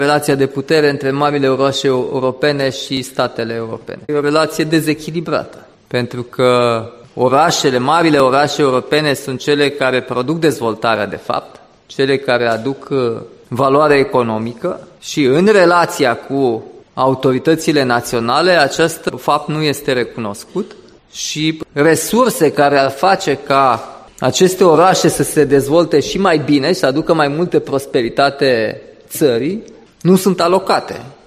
Sesiunile de dezbateri de la Muzeul de Artă
Deschiderea de dimineață  din Sala Barocă a Muzeului de Artă s-a bucurat de prezența Președintelui României , Nicușor Dan, care a primit aplauze după ce a menționat din start că trăim încă bucuria pentru rezultatele alegerilor din Republica Moldova.